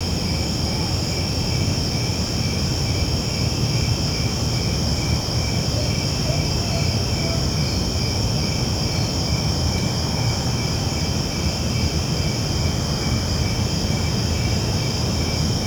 Index of /90_sSampleCDs/E-MU Producer Series Vol. 3 – Hollywood Sound Effects/Ambient Sounds/Night Ambience
NIGHT AMB02R.wav